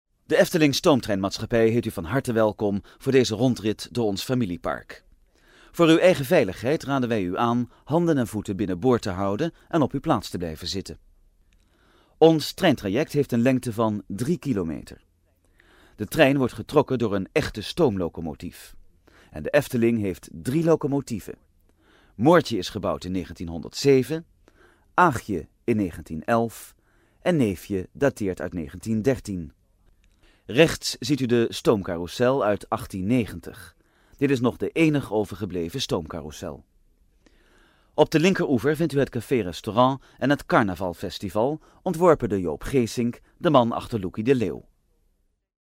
rondleiding-stoomtrein-efteling-blog-def.mp3